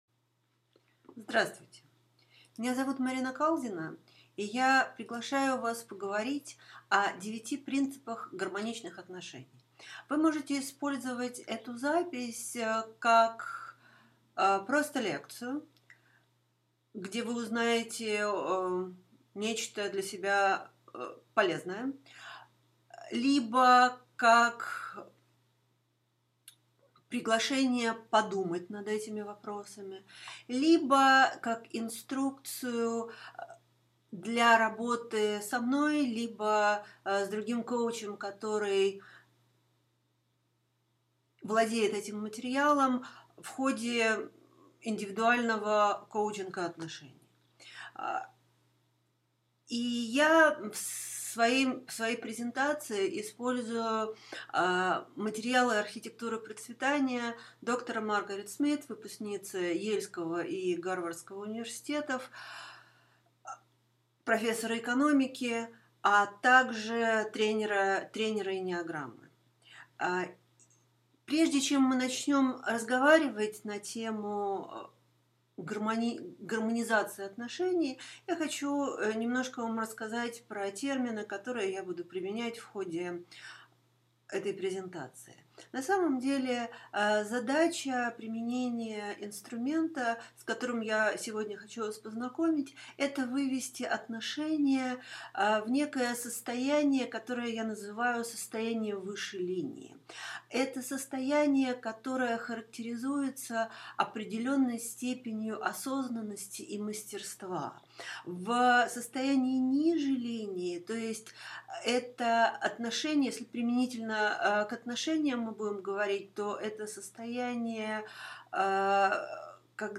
Аудиокнига 9 принципов гармоничных отношений | Библиотека аудиокниг